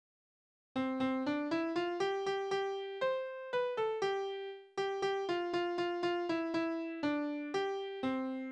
Tonart: C-Dur
Taktart: 2/4
Tonumfang: Oktave
Besetzung: vokal